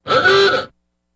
Car Horn Old Ahooga, Single